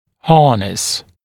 [‘hɑːnəs][‘ха:нэс]обуздывать, использовать; упряжь, сбруя